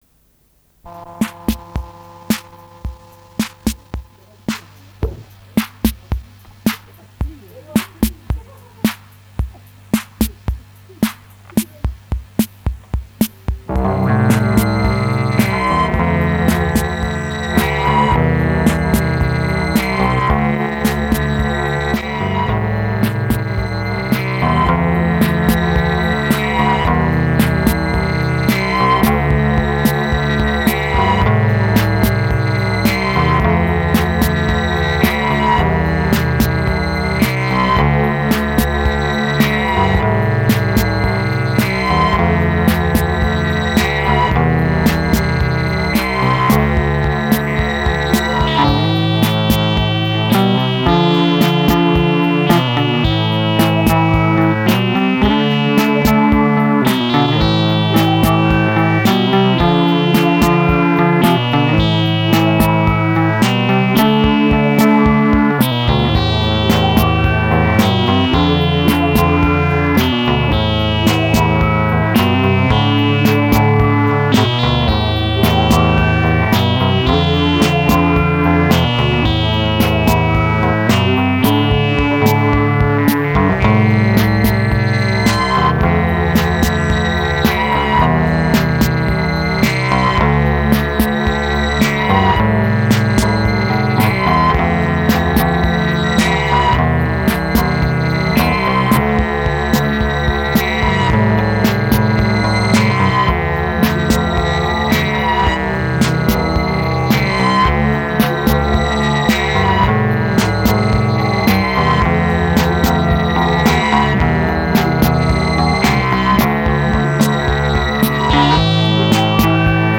Deux synthés, une basse, le quatre pistes. Une seule répétition, un morceau.